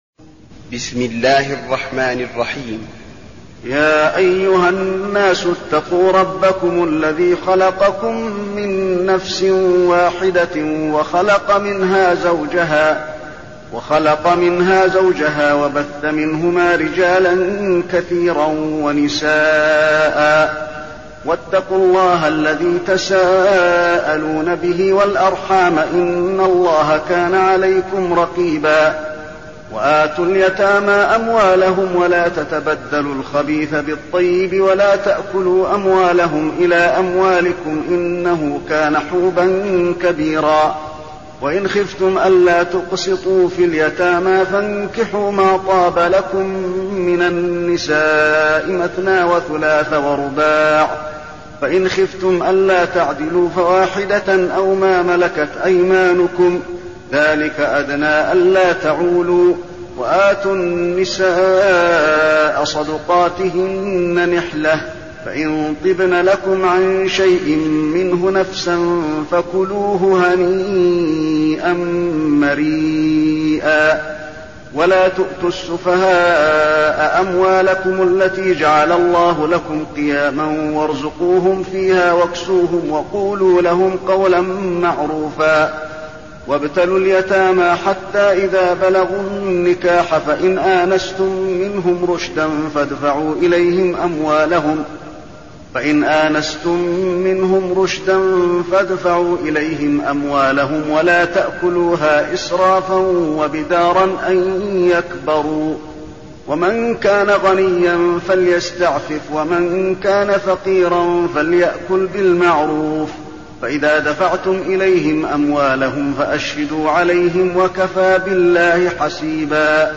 المكان: المسجد النبوي النساء